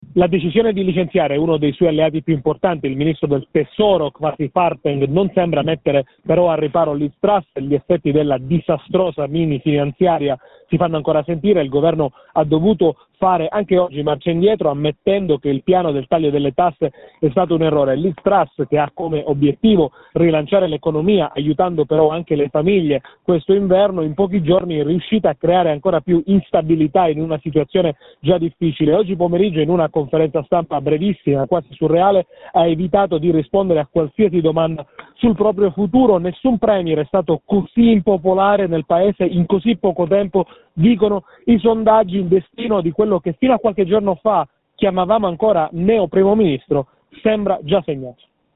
Dalla Gran Bretagna il nostro Collaboratore